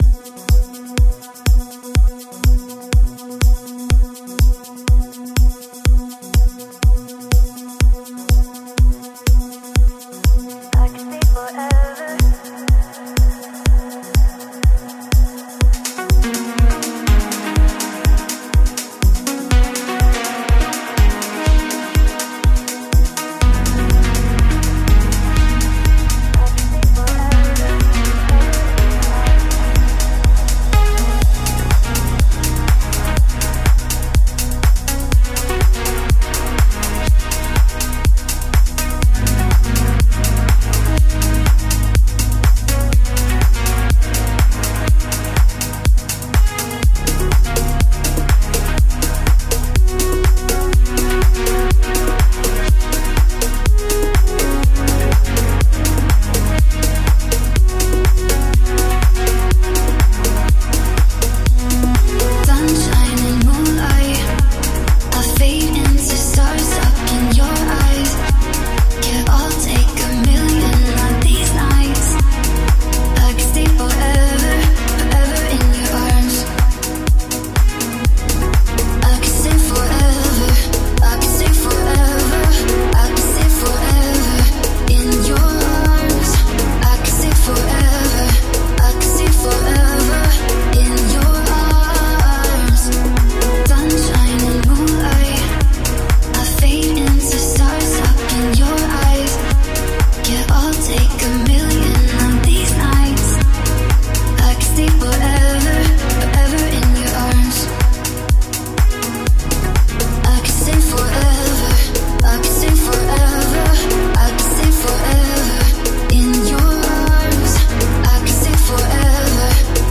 Melodic House/Breaks music
Progressive House
Deep House